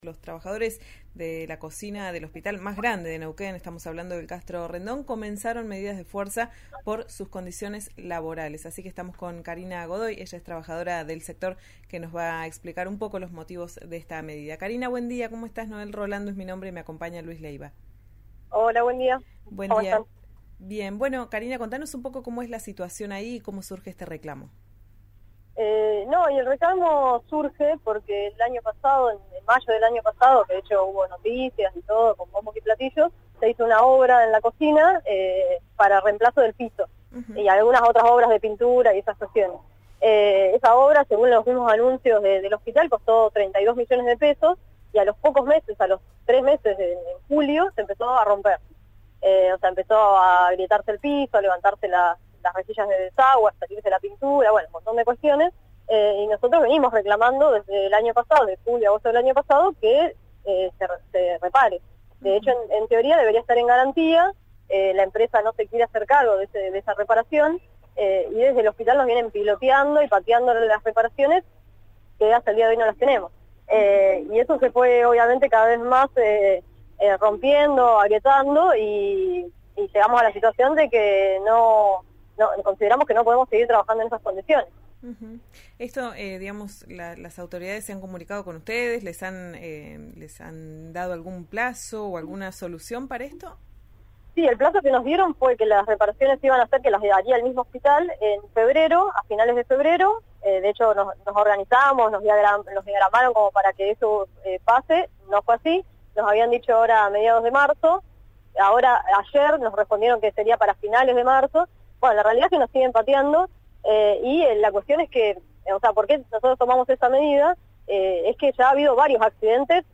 trabajadora del sector, en diálogo con «Ya es tiempo» por RÍO NEGRO RADIO